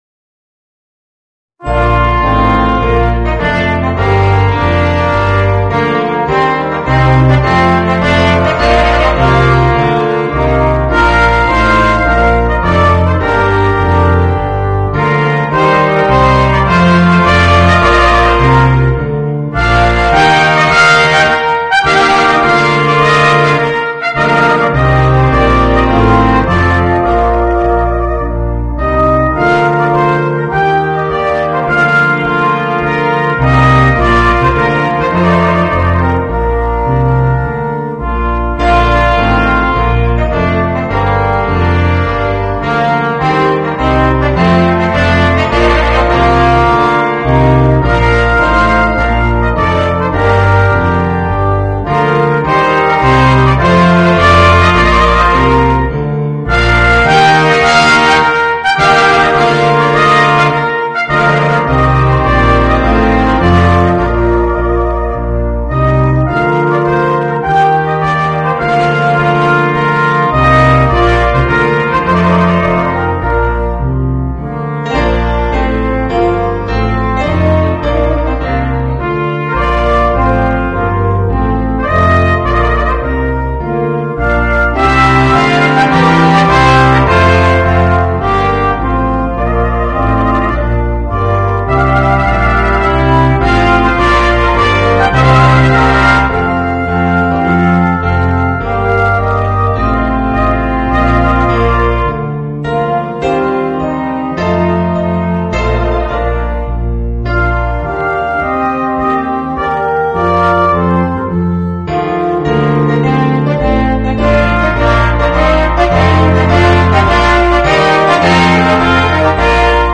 Voicing: 3 Trumpets, Trombone and Tuba